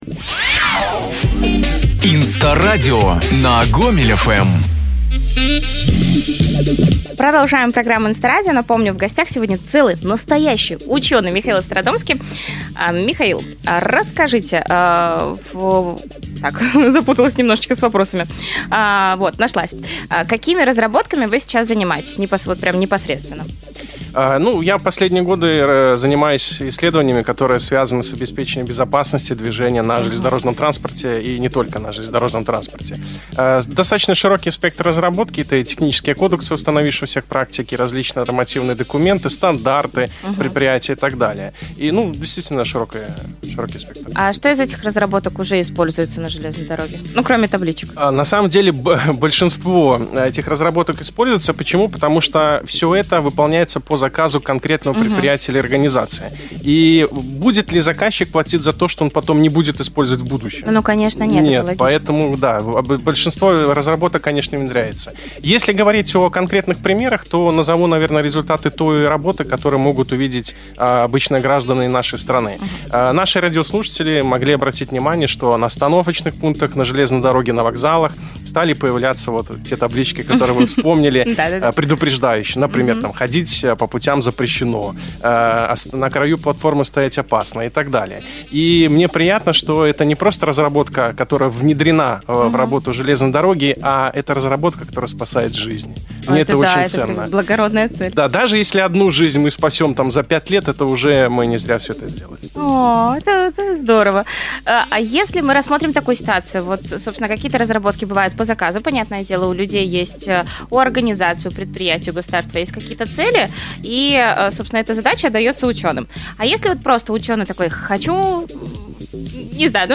28 ноября 2024 года с 11 до 12 часов в прямом эфире радио «Гомель ФМ» прозвучали компетентные ответы на вопросы о науке:
Ответы на серьезные (и не очень) вопросы можно узнать, послушав запись эфира.